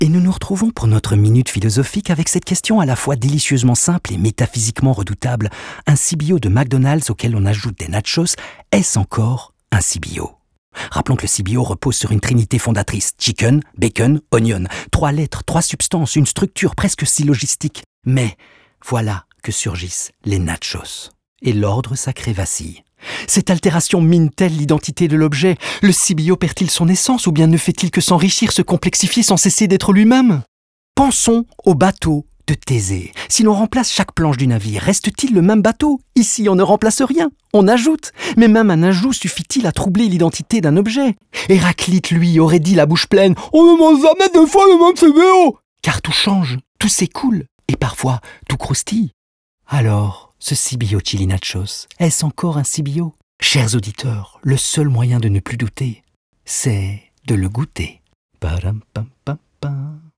Cerise sur le nacho, McDo et TBWA, on fait de ce débat une véritable question philosophique développée dans un spot radio de 1 minute. Ici, c’est à travers des grands penseurs tels qu’Héraclite qu’on essaye de savoir où s’arrête l’être, quand cesse-t-on d’être nous-mêmes…Bref, si un CBO avec des nachos reste un CBO.